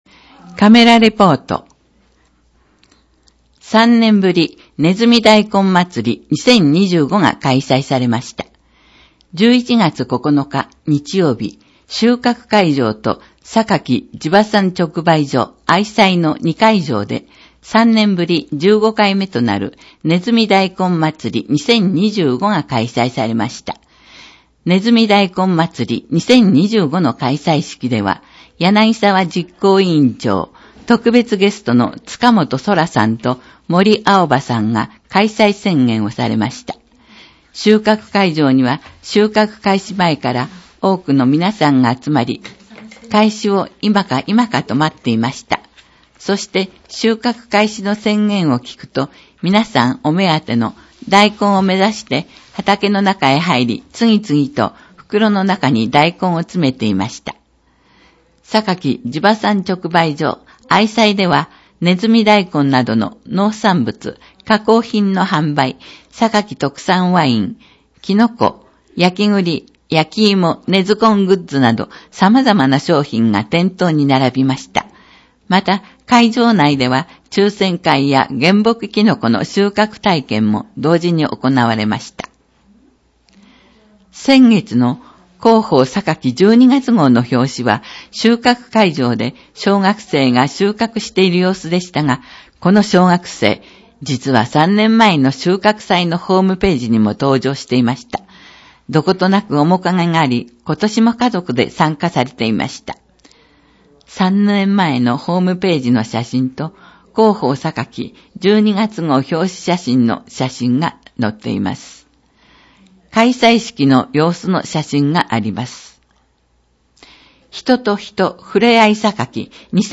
また、音訳ボランティアサークルおとわの会のみなさんによる広報の音訳版のダウンロードもご利用ください。